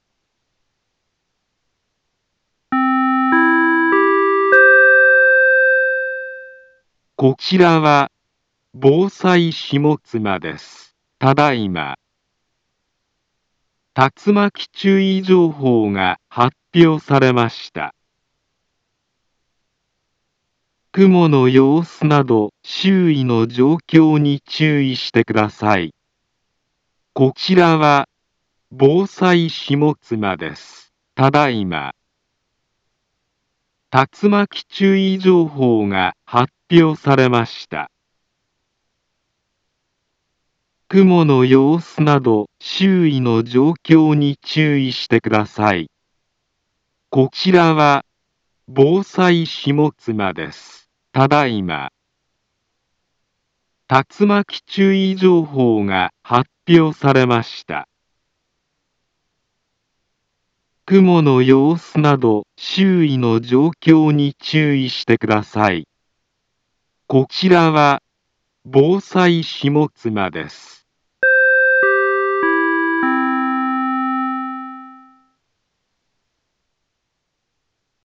Back Home Ｊアラート情報 音声放送 再生 災害情報 カテゴリ：J-ALERT 登録日時：2023-07-10 19:29:50 インフォメーション：茨城県南部は、竜巻などの激しい突風が発生しやすい気象状況になっています。